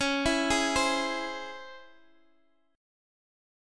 C#mM7 chord